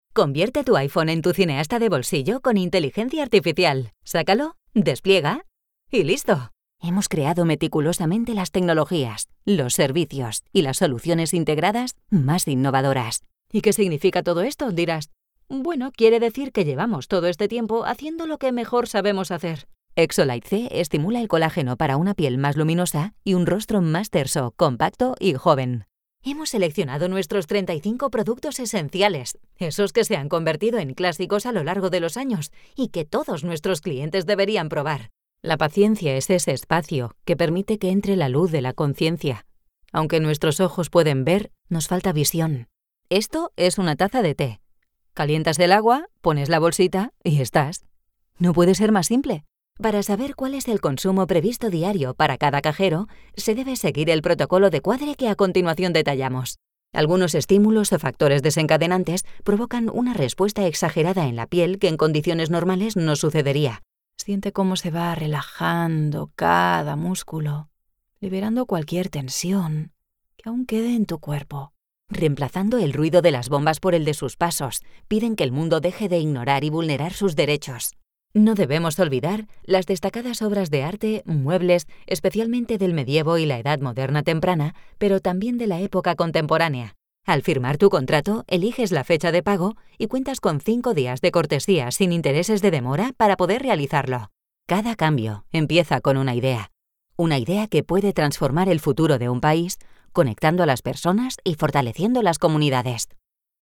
Vidéos d'entreprise
Corporate & professionnel  entreprise, finance, institutionnel
Neumann U87 Ai, Avalon V5, Apogee Duet, Pro Tools, cabine Studiobricks.